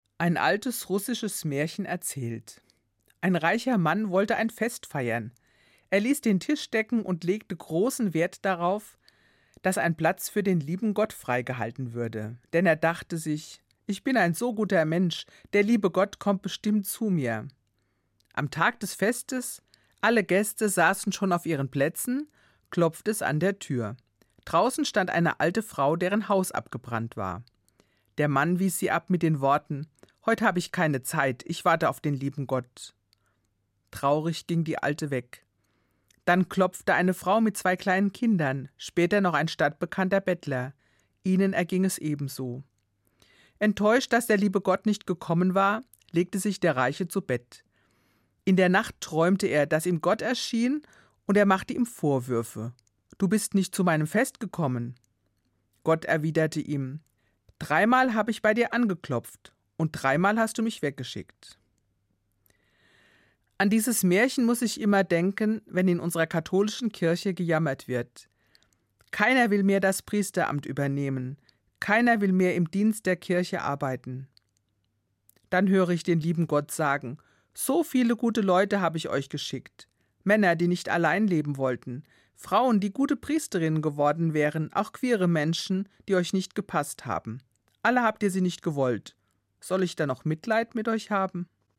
Katholische Gemeindereferentin im Ruhestand